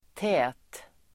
Uttal: [tä:t]